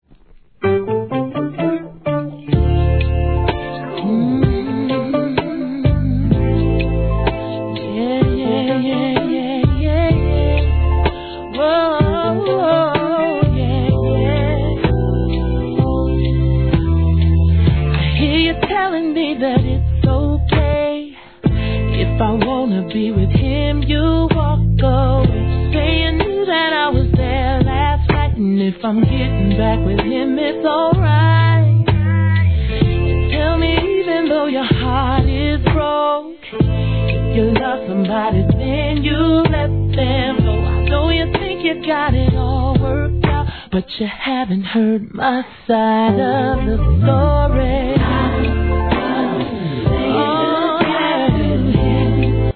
HIP HOP/R&B
2002年のGOODスローJAM♪ 後半の哀愁ギターからの盛り上がりが最高です。